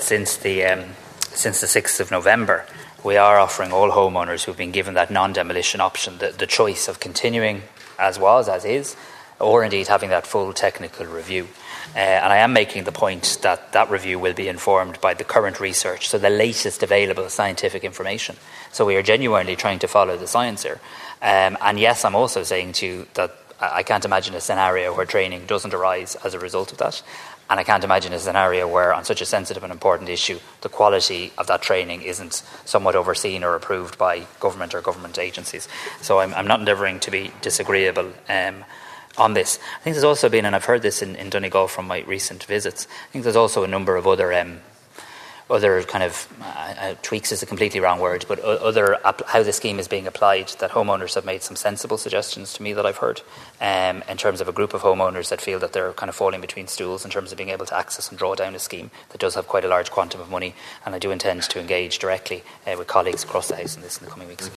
The controversial training conducted by Engineers Ireland on the outdated IS465 standard was raised in the Dáil.
Tánaiste Simon Harris says steps will be taken after the review is complete: